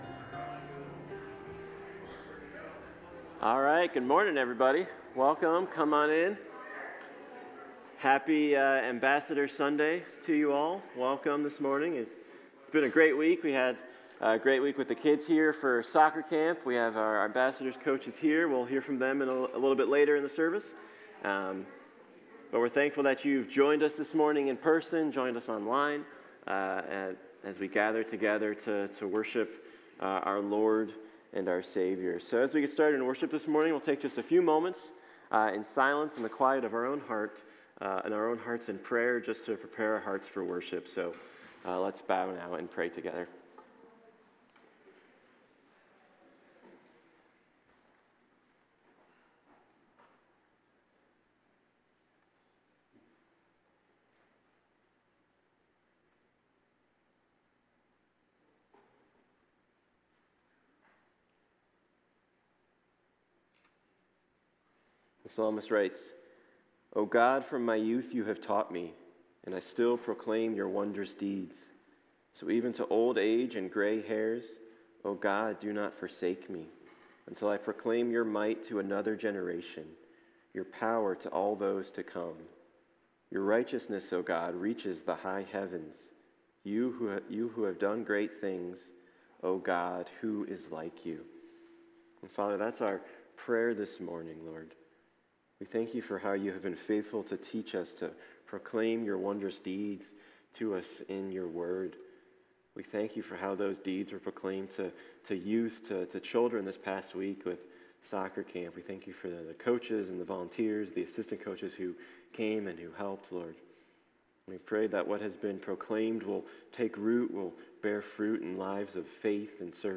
Passage: Mark 12:28-34 Service Type: Sunday Morning Click on title above to watch video.